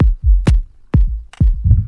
Electrohouse Loop 128 BPM (9).wav